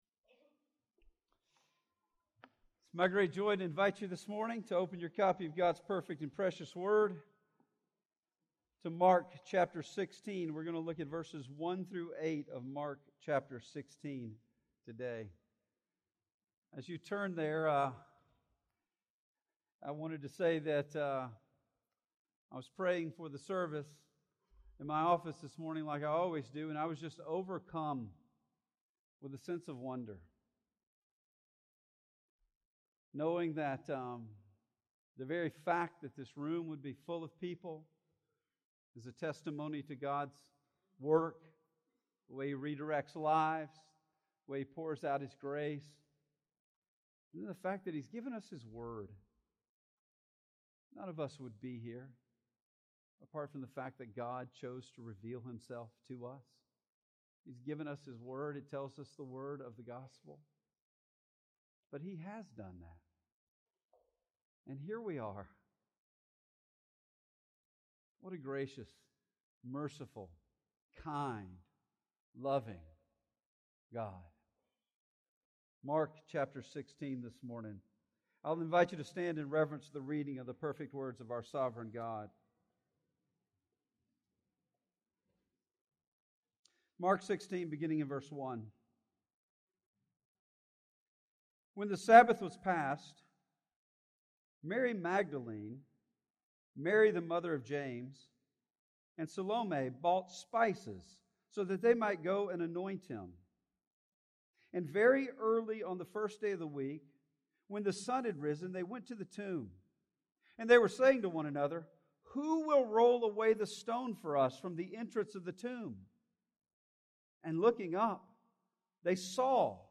Sermon Audio Sermon Video Sermon Notes Sermon Audio http